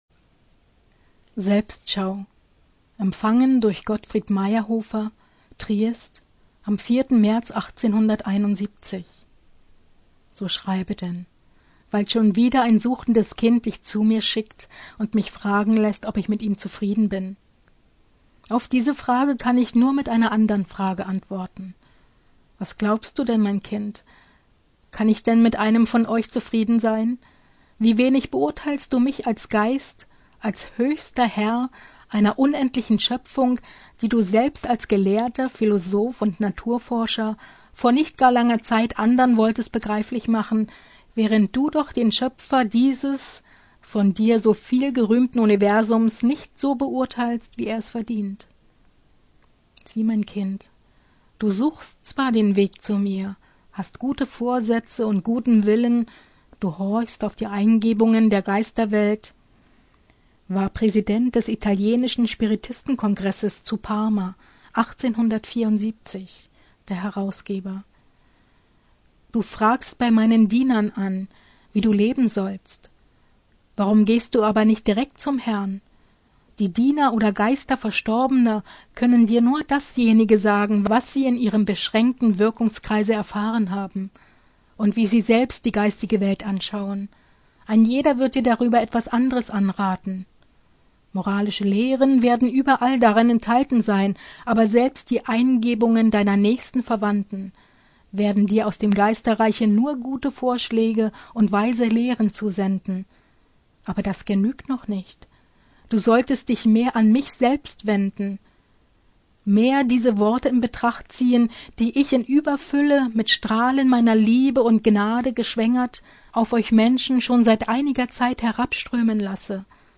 Lebensgarten Gottfried Mayerhofer H�rbuch